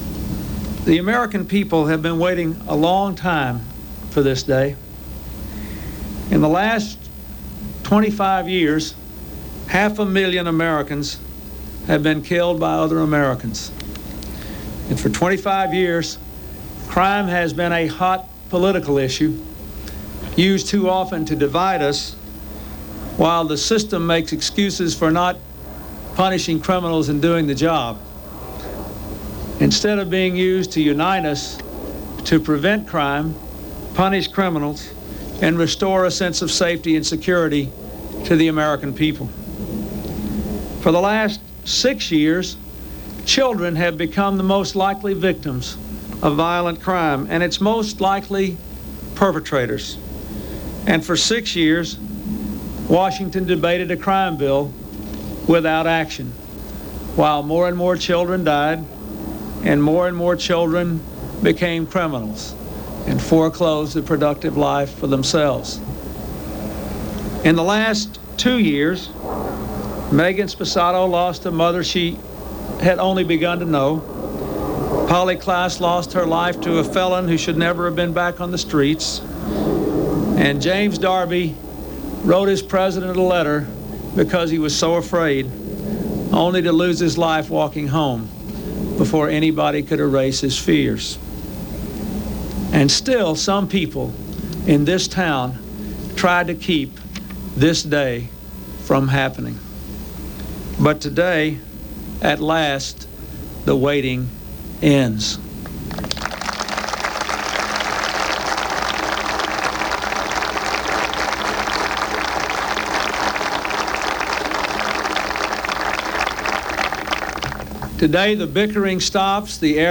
Speakers Clinton, Bill, 1946-
Broadcast on CNN, Sept. 13, 1994.